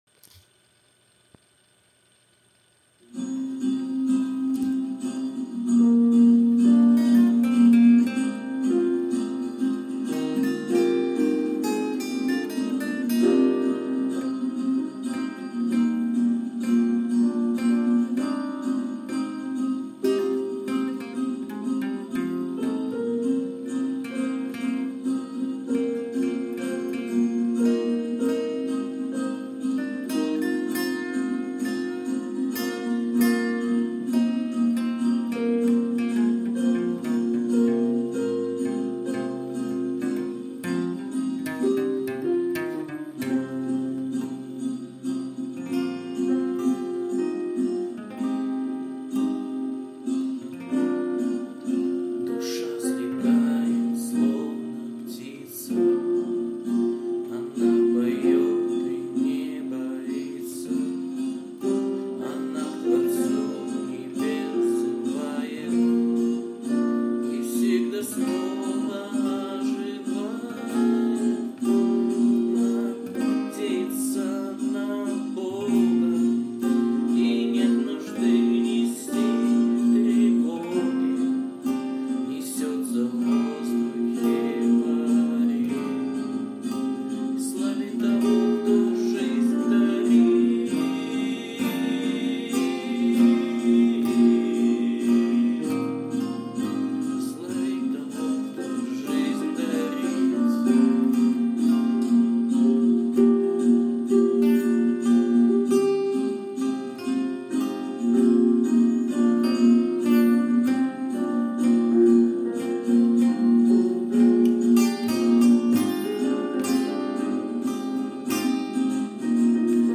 79 просмотров 44 прослушивания 2 скачивания BPM: 96